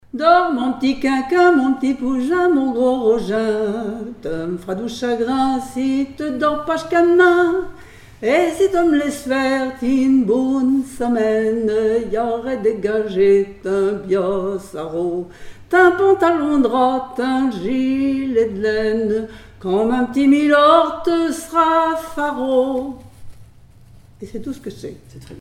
Berceuses diverses
enfantine : berceuse
Pièce musicale inédite